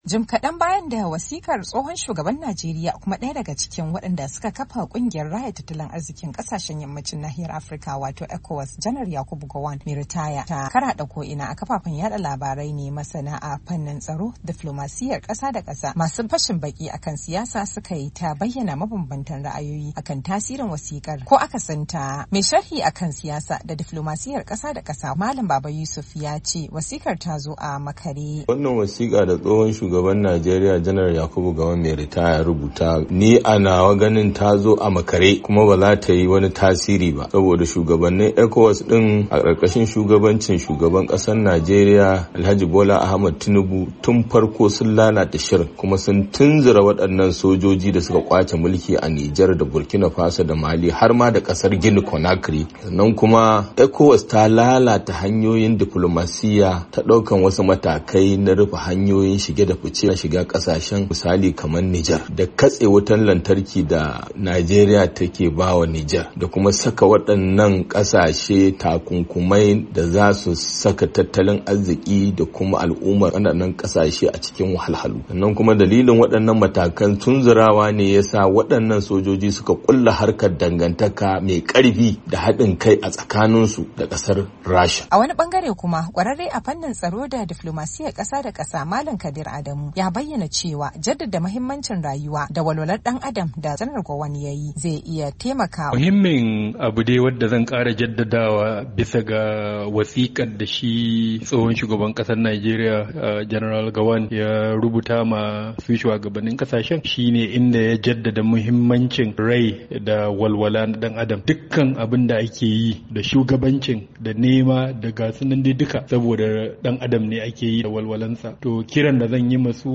A nata bayanin, wata matashiya ‘yar Jamhuriyyar Nijar tace al’umar kasarta na maraba da sakon na tsohon Shugaban Najeriya, tana mai cewa babu fatan da ‘yan Nijar ke da shi a halin yanzu kamar a bude musu iyakokin kasashen dake makwabtaka da su gabanin tunkarowar watan Ramadan.